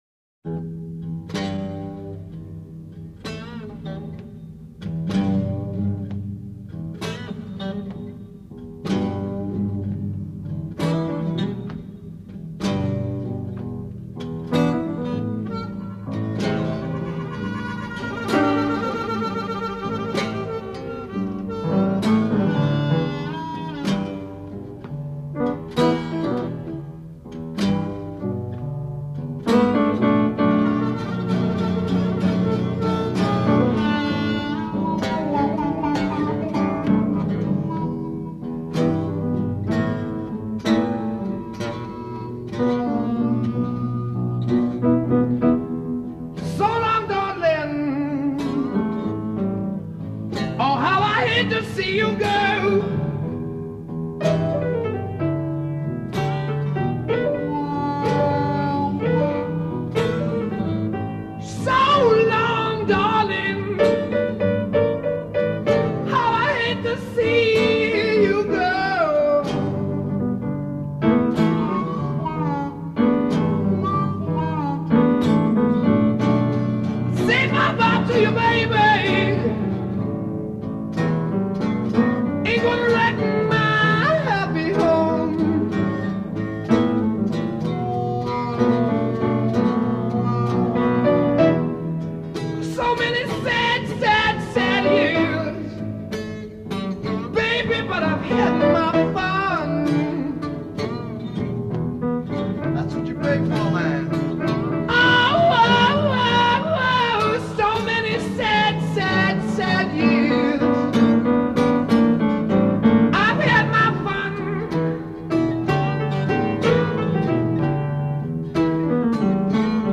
Recorded September 1968 at De Lane Lea Studios, London.
vocals & harmonica
guitar
piano
V1 12 Guitar solo, add harmonica and piano.
V2 12 Solo vocal over guitar and piano a
V4 12 Harmonica solo.
Led Zeppelin Outline Transatlantic Psychedelic Blues